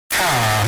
Minecraft Villager Bass Boosted